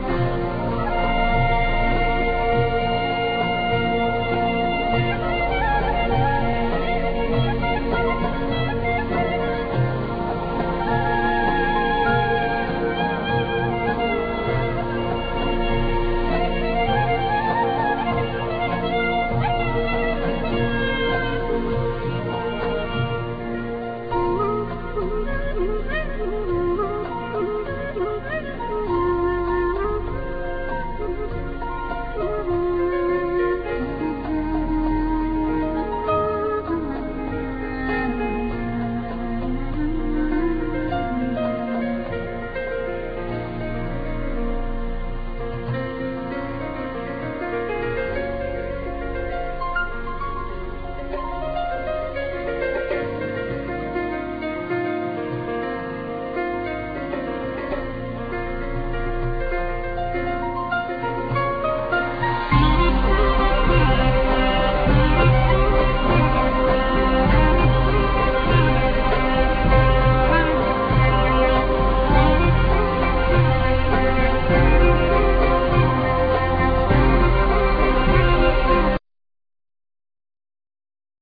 Vocals
Piano
Drums
Double bass, E-bass
Bakllama, Lute, Percussions
Accordion
Solo Clarinet